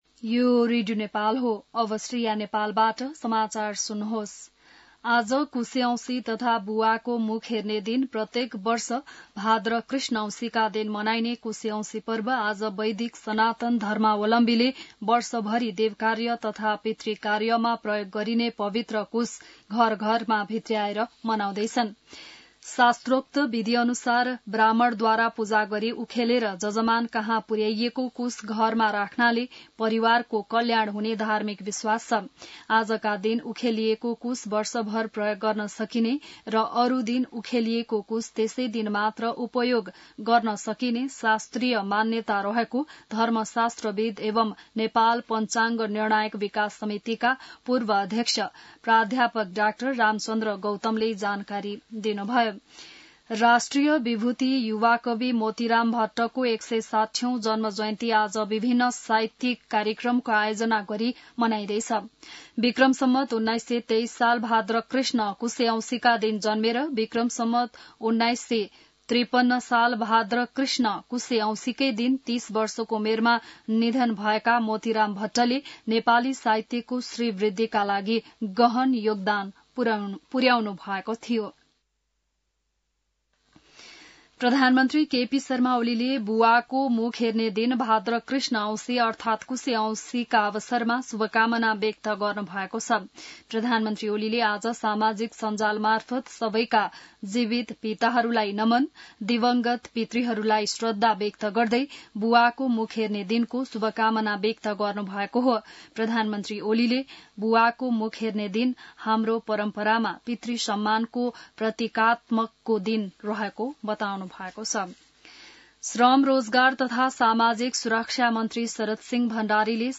बिहान १० बजेको नेपाली समाचार : ७ भदौ , २०८२